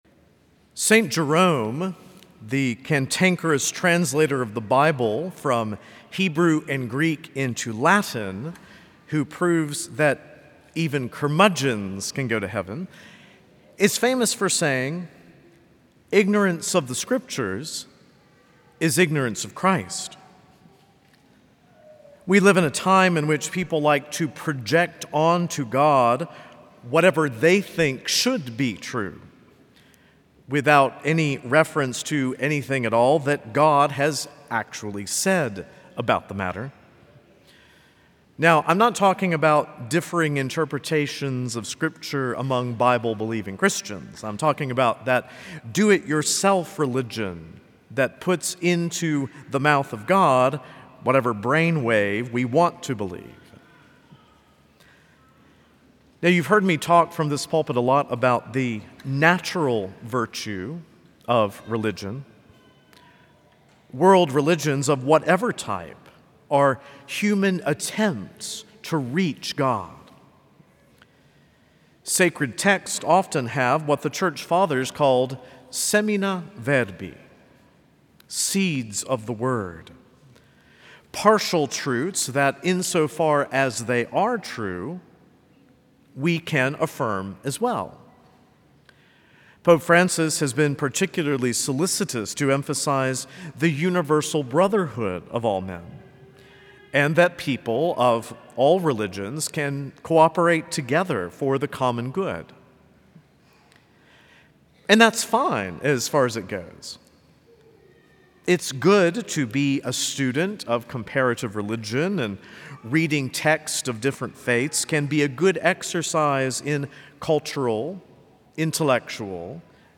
From Series: "Homilies"